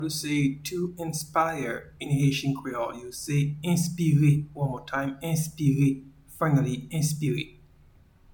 Pronunciation and Transcript:
to-inspire-in-Haitian-Creole-Enspire.mp3